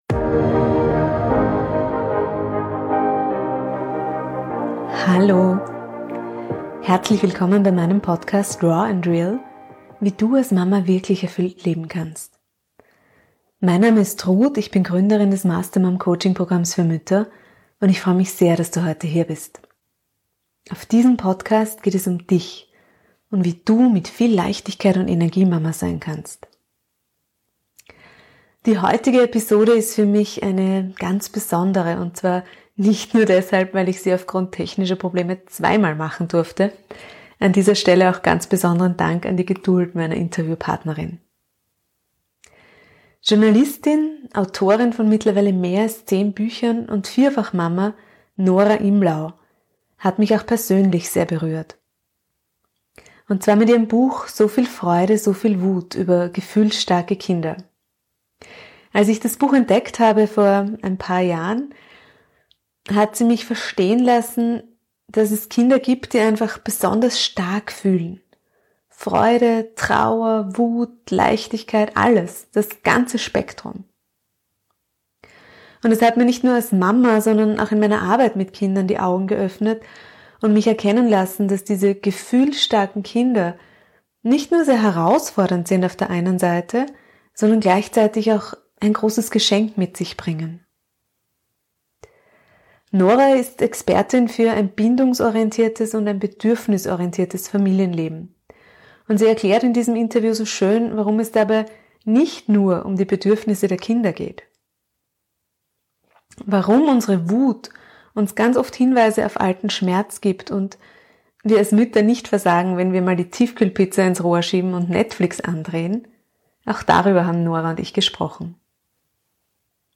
#71 Bindungsorientiertes Familienleben und gefühlsstarke Kinder. Interview